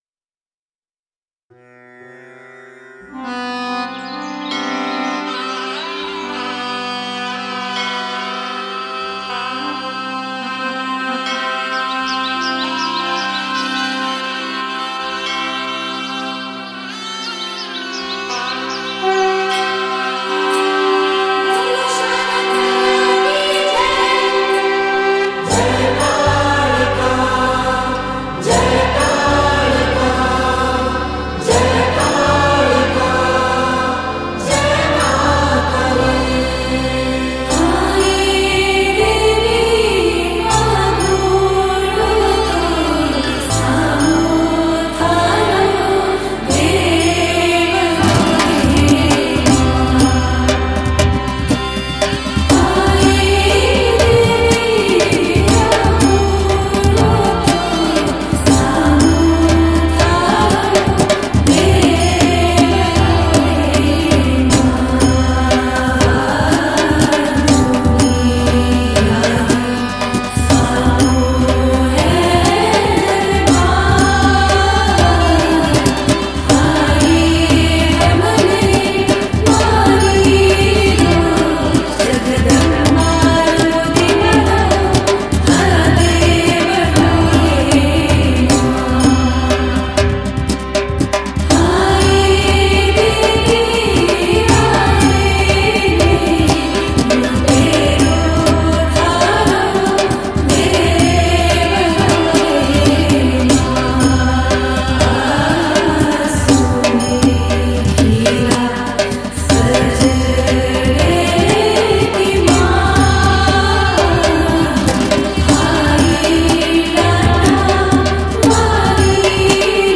[Devotional]